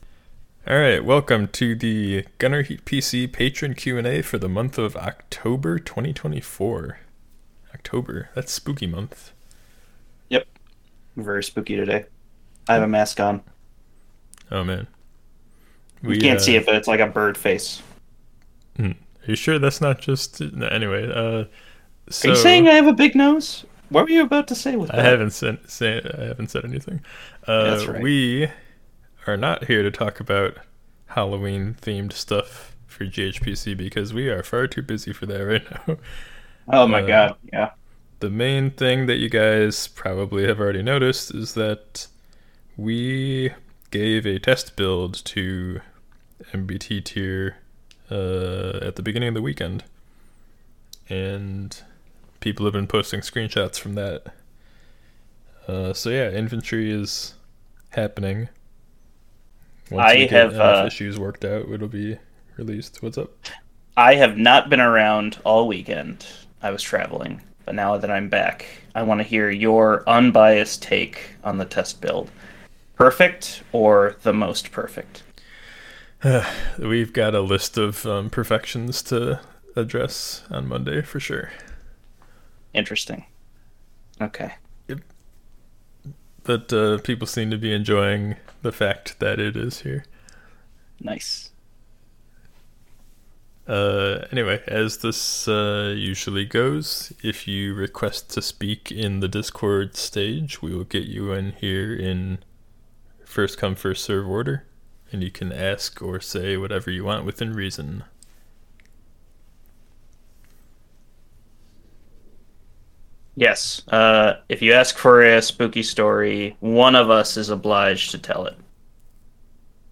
This is the recording from the latest Q&A session for anyone who couldn't make it.
The Q&A sessions are held using the patron stage there.